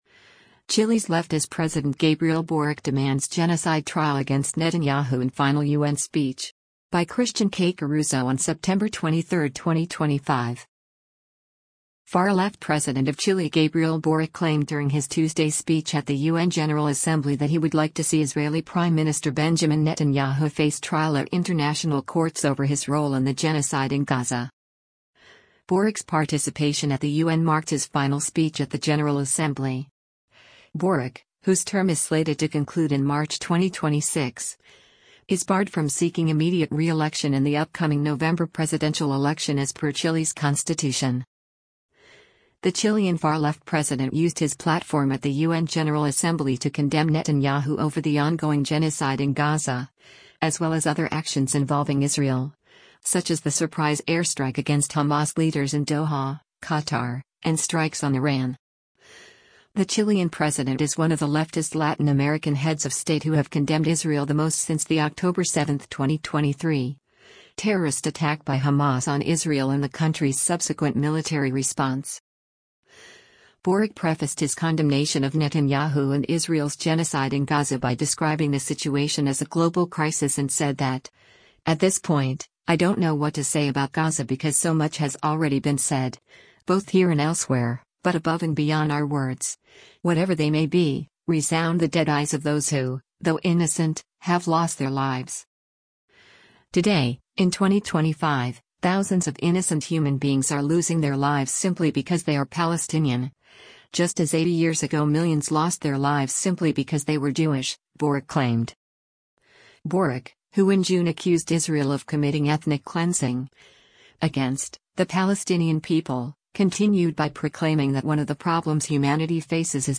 NEW YORK, NEW YORK - SEPTEMBER 23: President of Chile Gabriel Boric speaks during the 80th